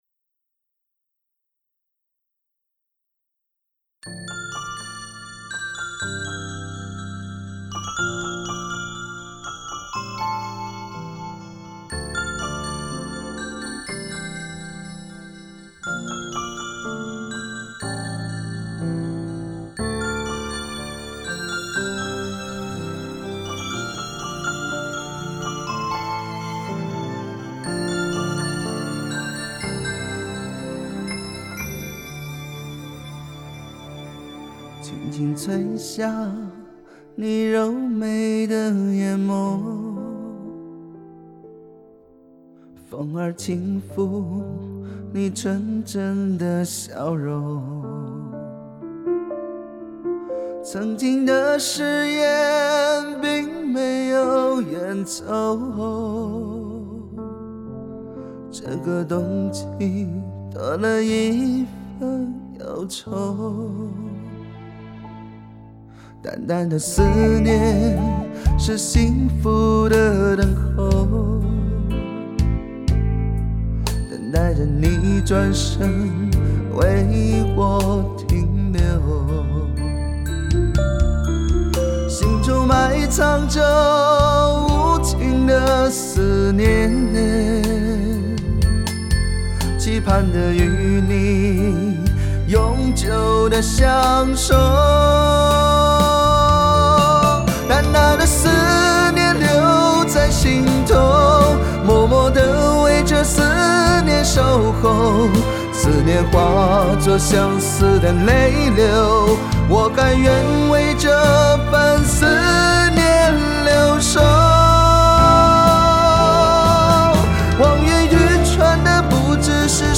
具有磁性的嗓音，独特的演唱风格颇受网友喜爱